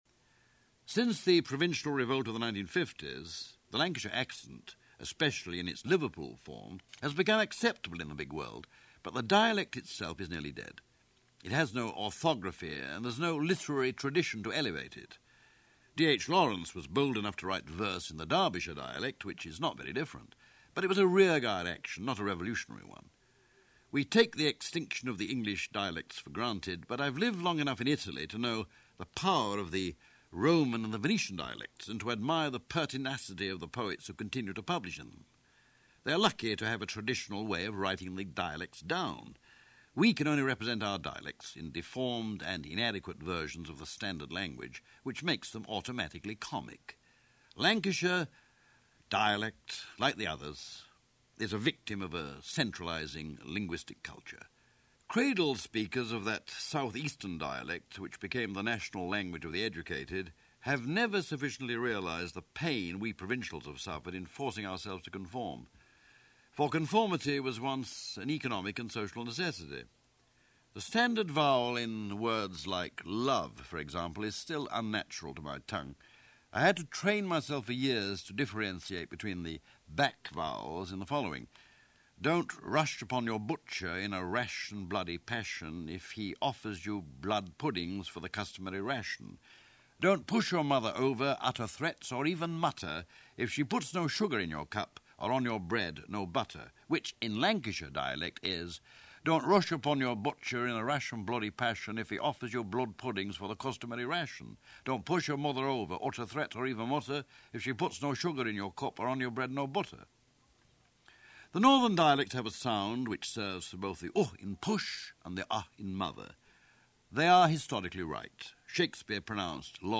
Dialect-poem-from-Little-Wilson-Big-God.mp3